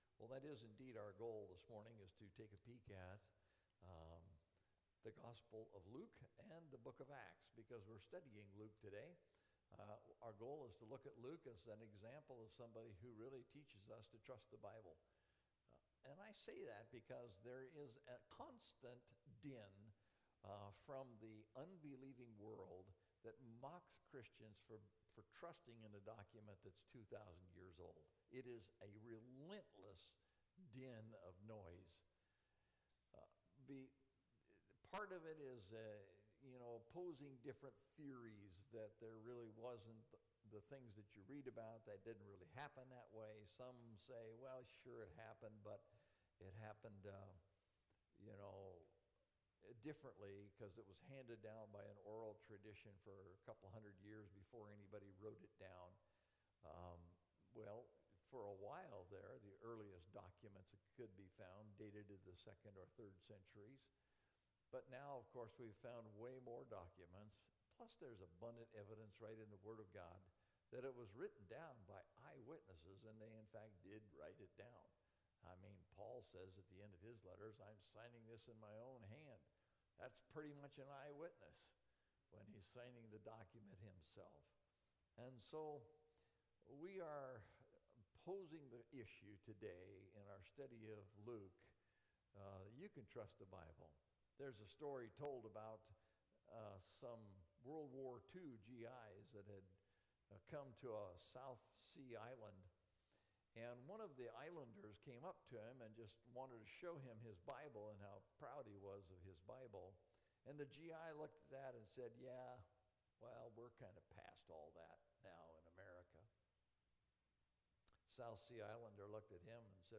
Luke – A Lesson In Trusting Our Bible (Luke & Acts) – Mountain View Baptist Church